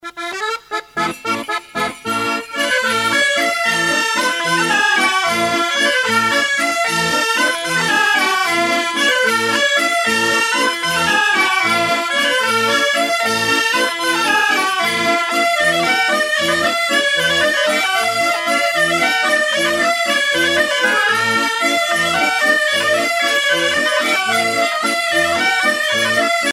danse : bourree ;
Pièce musicale éditée